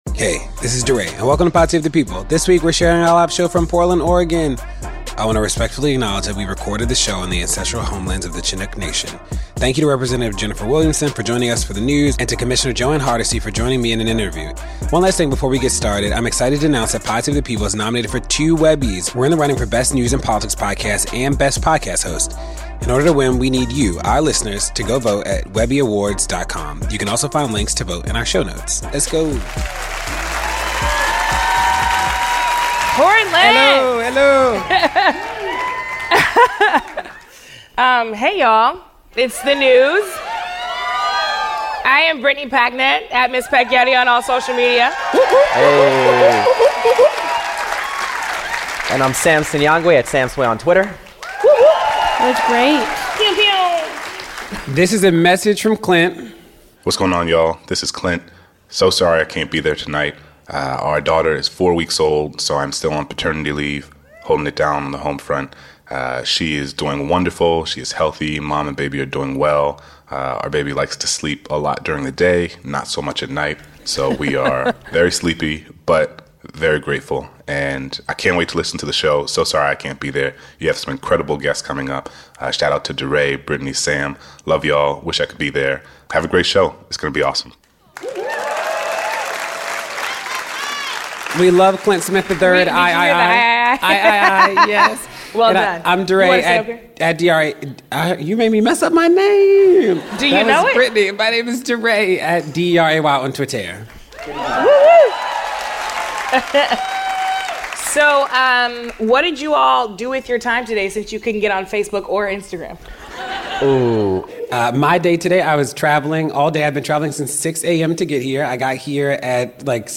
Inside Outside Strategy (LIVE from Portland, OR)